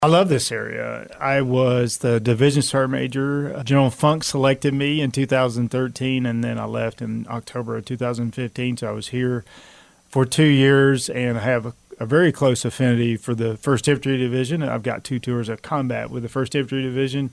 The Manhattan Area Chamber of Commerce held its monthly military relations luncheon on Friday with its featured speaker being retired Sergeant Major of the Army Michael Tony Grinston who was recently appointed the CEO of Army Emergency Reserve.